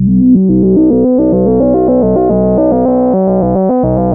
JUP 8 B2 11.wav